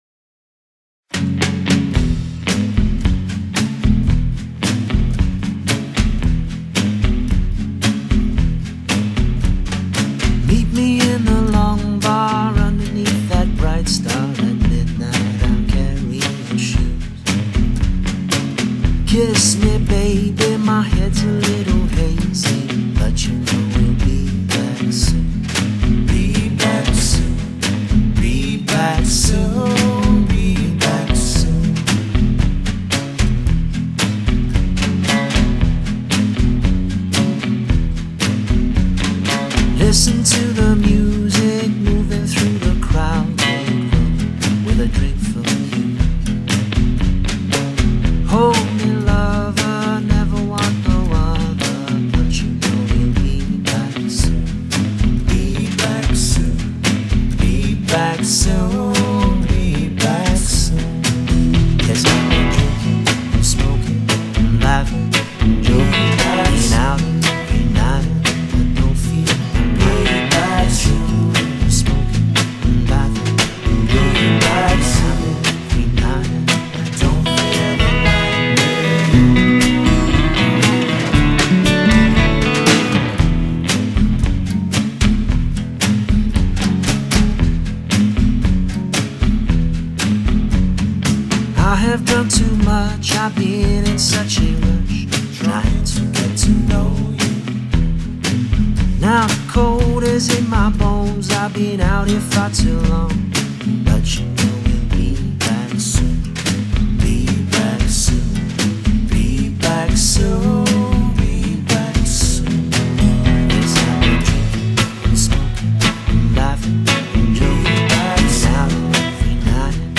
una melodía alegre y relajada